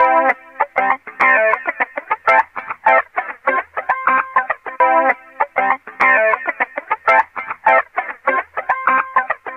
Loops guitares rythmique- 100bpm 3
Guitare rythmique 53